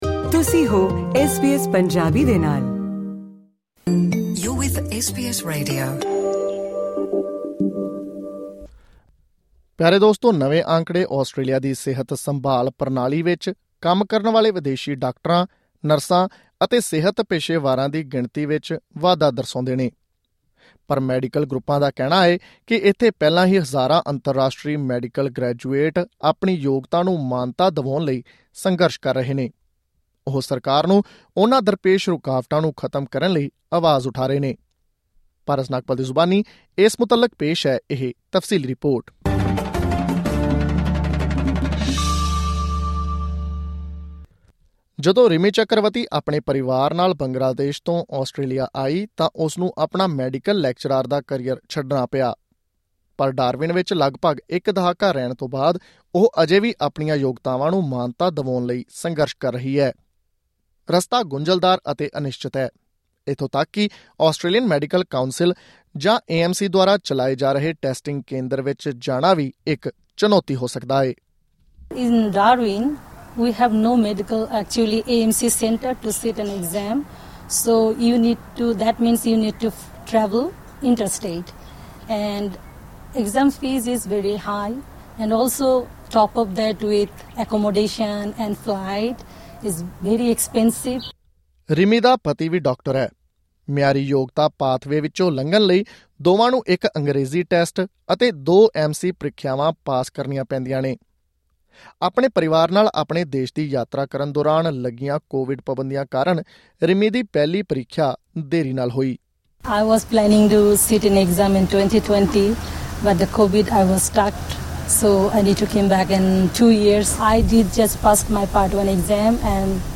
ਹੋਰ ਜਾਣਕਾਰੀ ਲਈ ਇਸ ਆਡੀਓ ਰਿਪੋਰਟ ਨੂੰ ਸੁਣੋ।